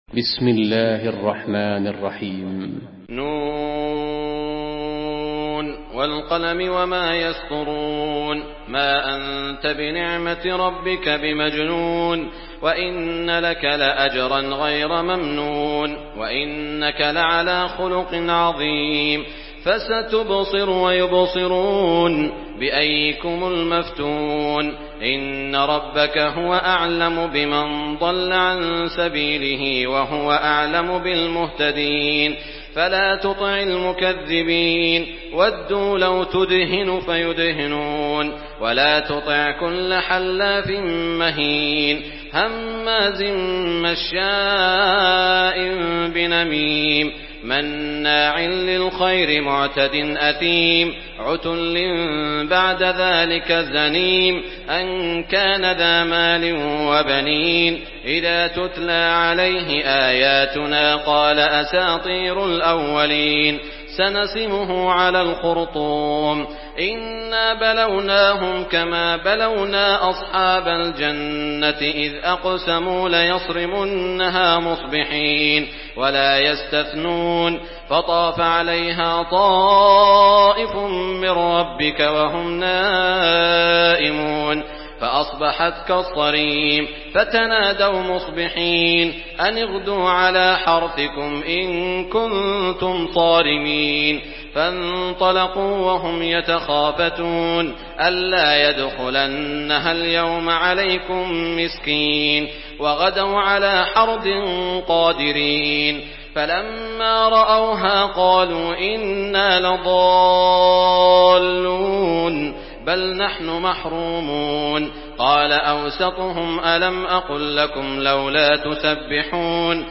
Surah আল-ক্বালাম MP3 by Saud Al Shuraim in Hafs An Asim narration.
Murattal Hafs An Asim